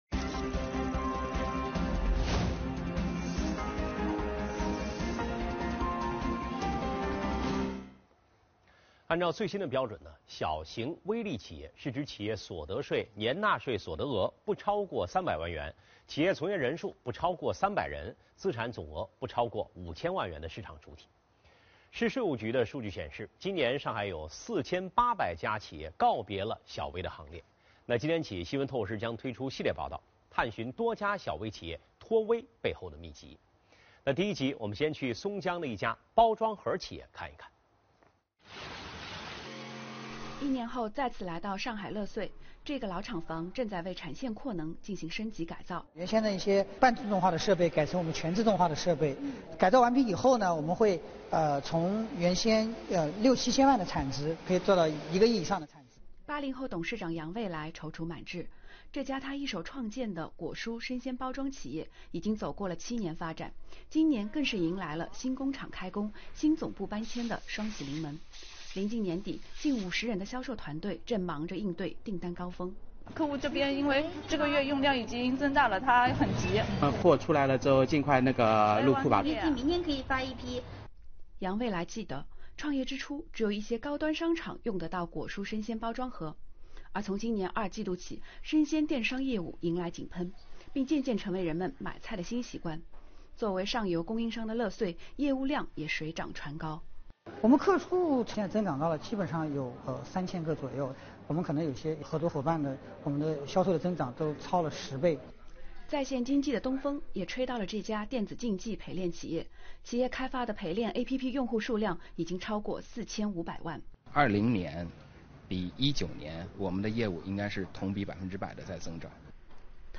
本周，上海电视台《新闻透视》栏目推出3集系列报道，探寻多家小微企业脱“微”背后的秘笈。